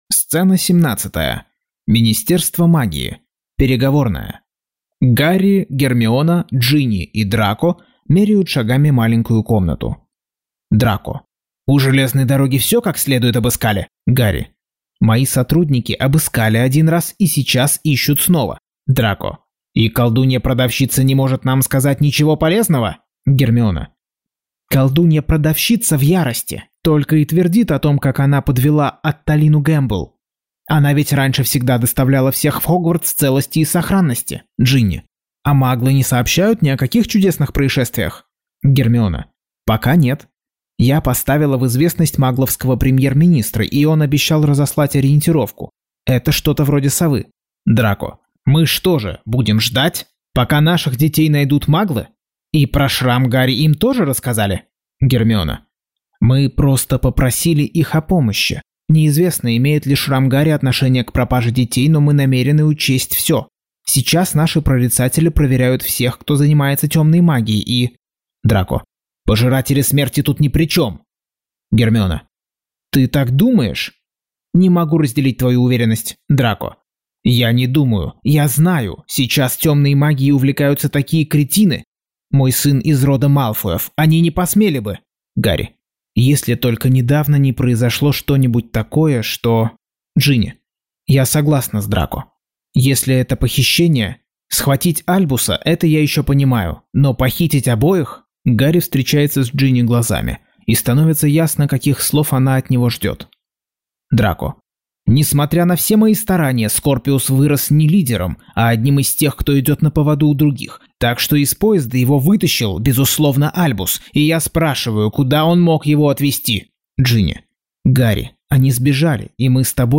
Аудиокнига Гарри Поттер и проклятое дитя. Часть 12.